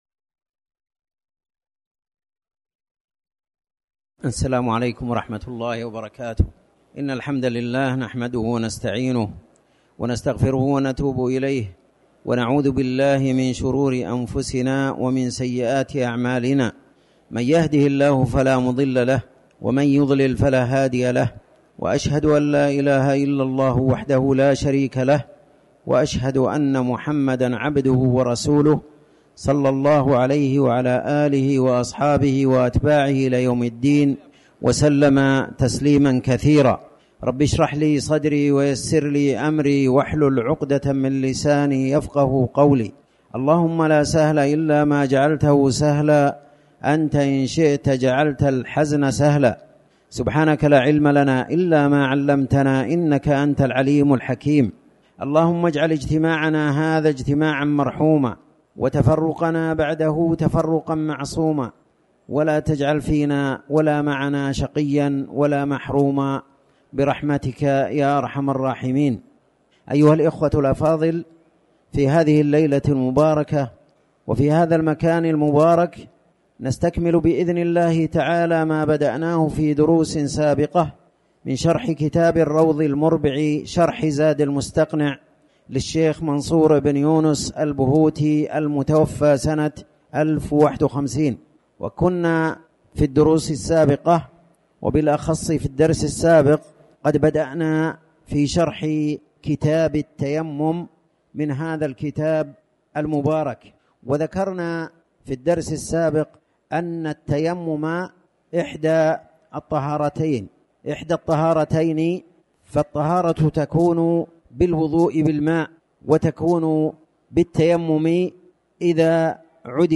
تاريخ النشر ١ جمادى الأولى ١٤٤٠ هـ المكان: المسجد الحرام الشيخ